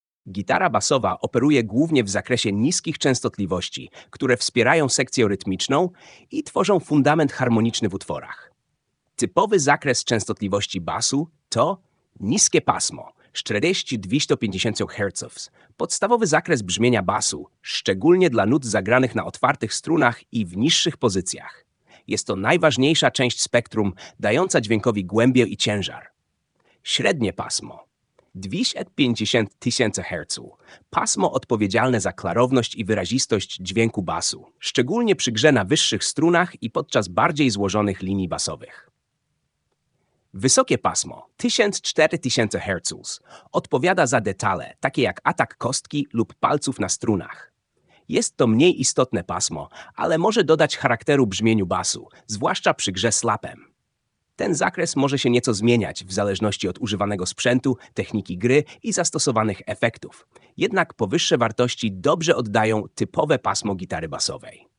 Gitara basowa pasmo akustyczne
Gitara basowa operuje głównie w zakresie niskich częstotliwości, które wspierają sekcję rytmiczną i tworzą fundament harmoniczny w utworach.
• Niskie pasmo: 40-250 Hz – podstawowy zakres brzmienia basu, szczególnie dla nut zagranych na otwartych strunach i w niższych pozycjach.
Gitara-basowa-pasmo.mp3